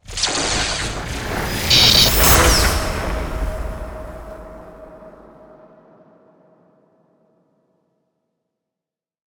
Magic Spell_Electricity Spell_1.wav